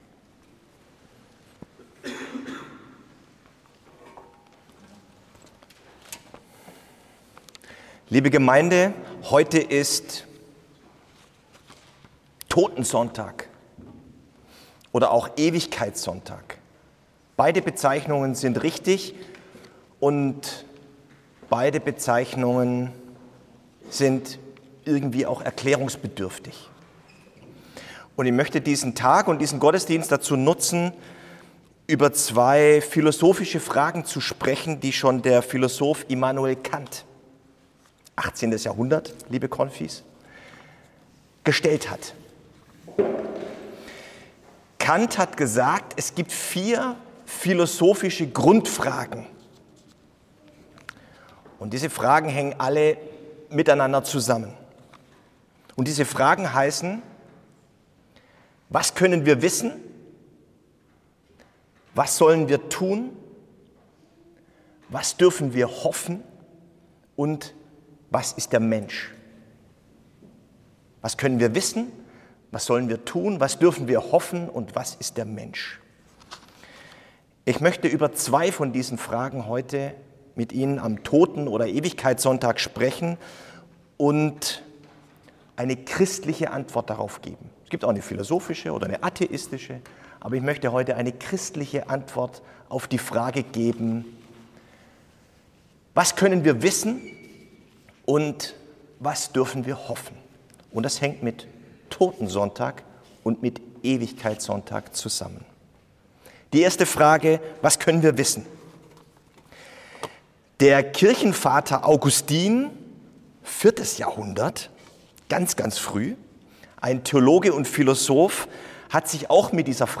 Auf zwei dieser Fragen gebe ich in dieser Predigt eine Antwort und zwar aus christlicher Sicht: Was können wir wissen?